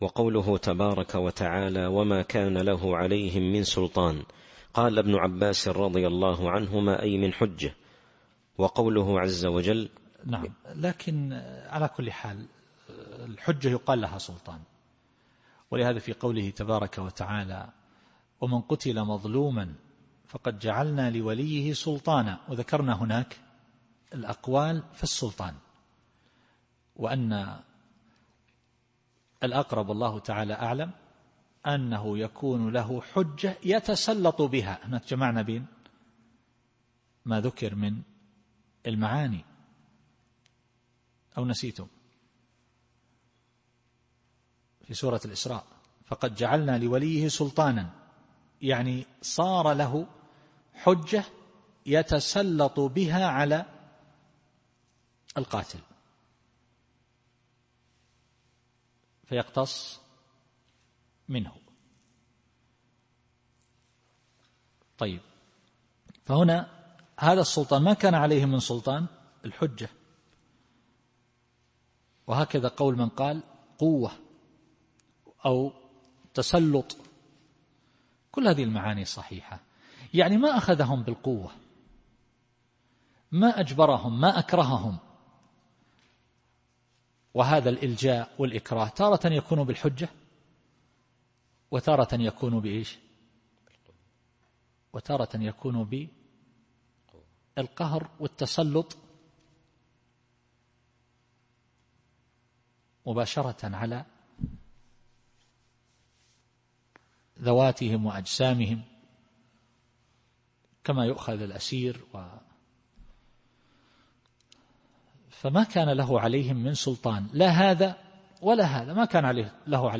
التفسير الصوتي [سبأ / 21]